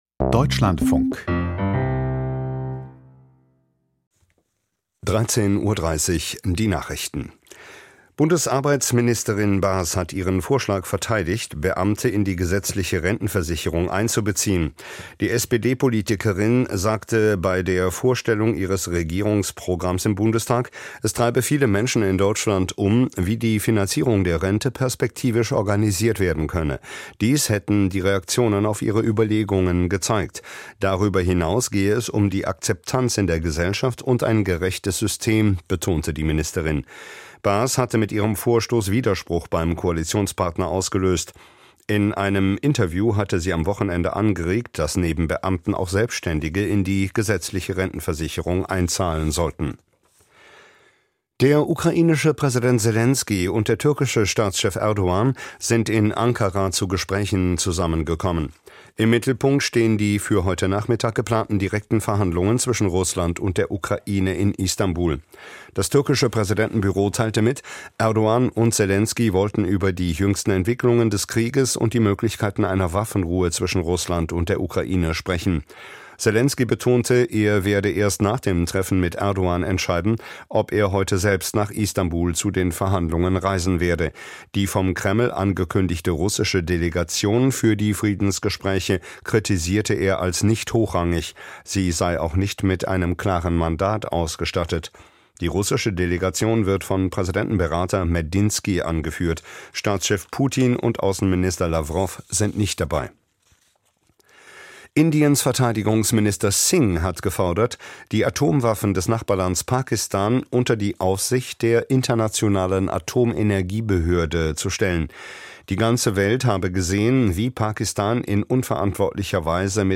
Die Nachrichten vom 15.05.2025, 13:30 Uhr
Aus der Deutschlandfunk-Nachrichtenredaktion.